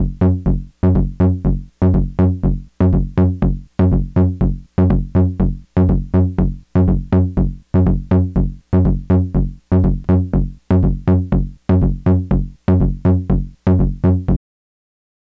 I will upload some audio in the next couple days but the sound is impacted by sweeping the filter but it is a very raw signal and does not seem to be affected by any of the other parameters.